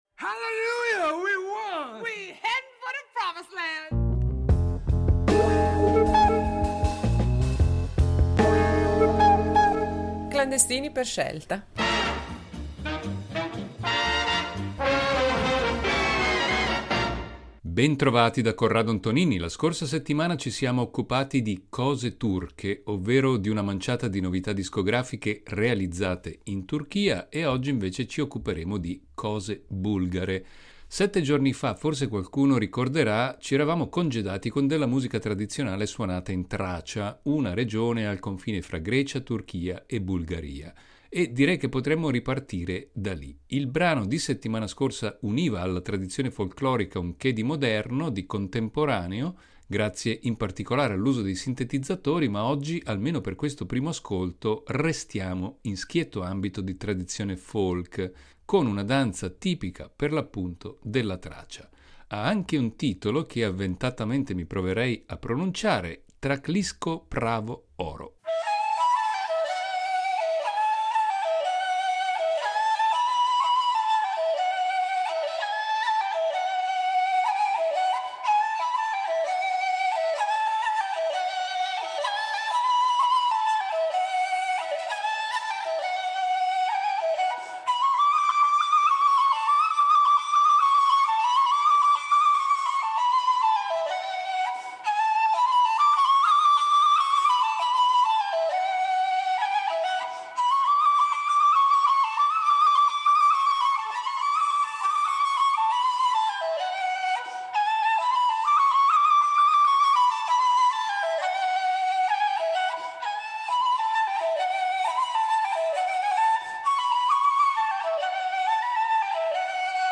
Musica dalla Bulgaria fra tradizione e contaminazione.
Dai temi d’impianto folklorico e nel solco della tradizione vocale bulgara che anni fa ci fece scoprire il “coro delle voci bulgare”, fino al tentativo di annettere quella tradizione al mare magnum della world music o alle sperimentazioni a cavallo fra jazz e rock.